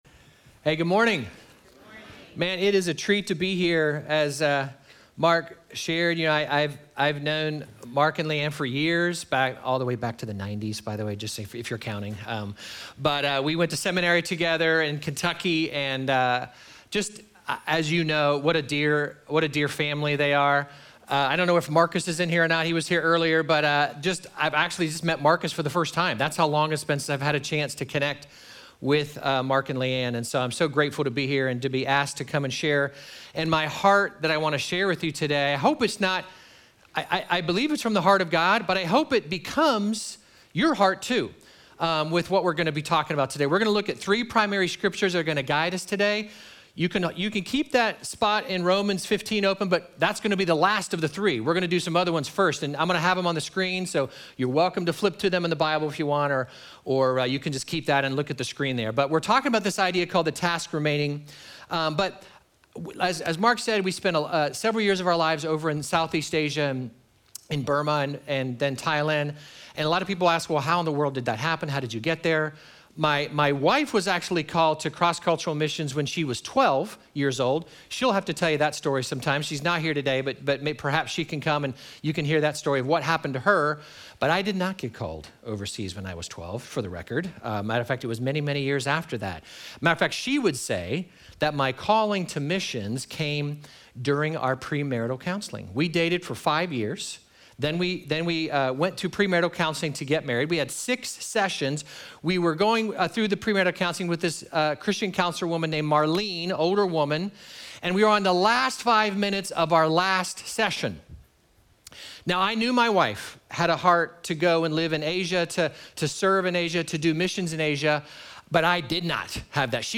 Sermon text: Romans 15:20-21